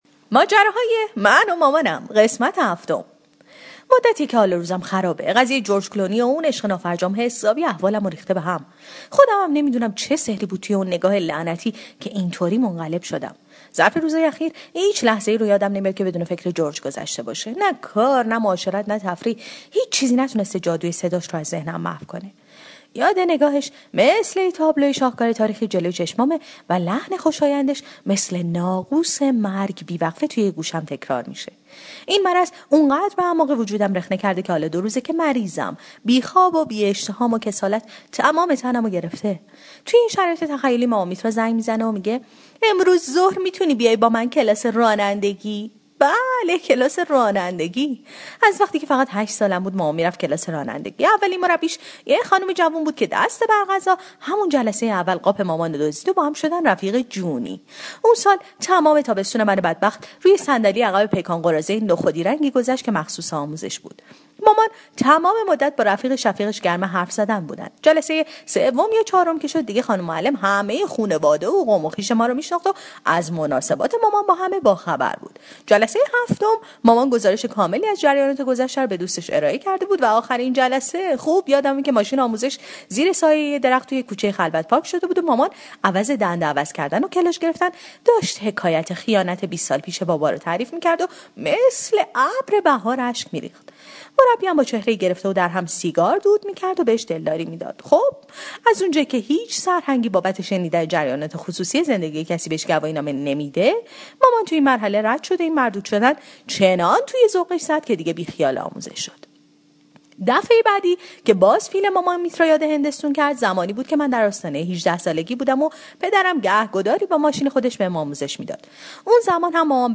طنزصوتی/ ماجراهای من و مامانم ۷